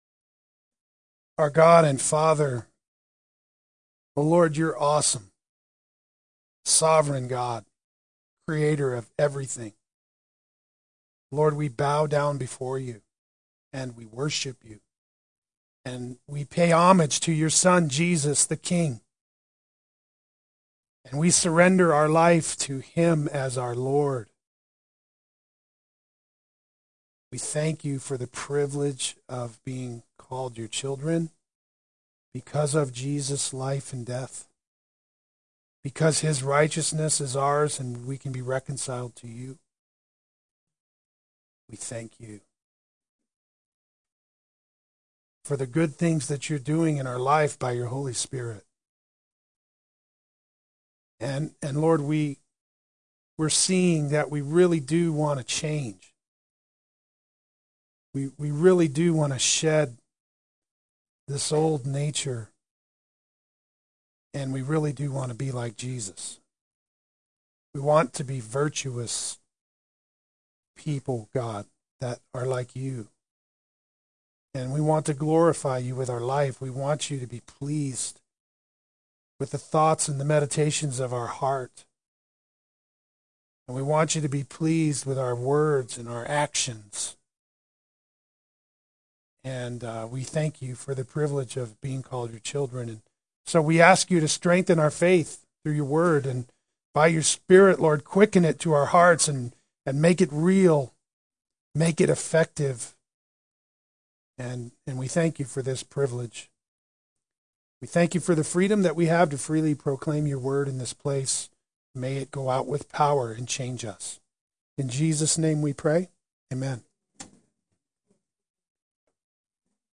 Play Sermon Get HCF Teaching Automatically.
Through the Word by the Spirit Adult Sunday School